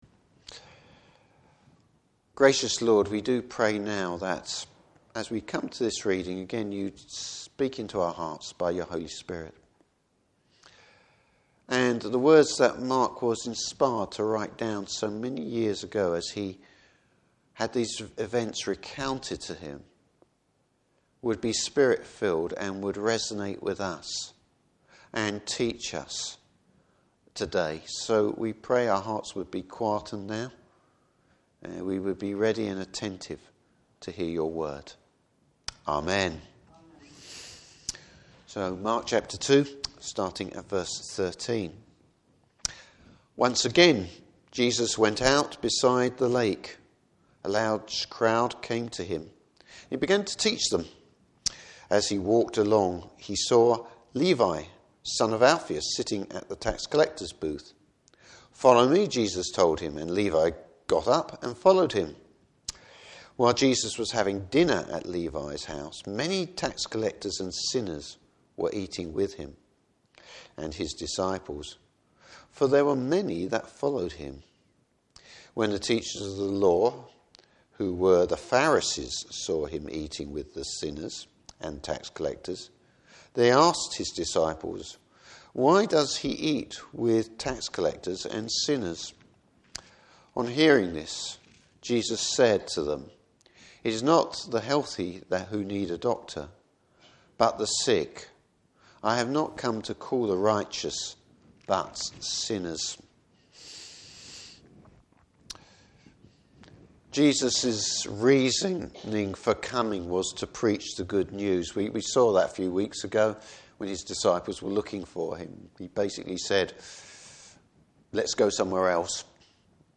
Service Type: Morning Service Who needs or will accept Jesus?